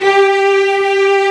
Index of /90_sSampleCDs/Roland - String Master Series/CMB_Combos 2/CMB_Full Section
STR STRING0A.wav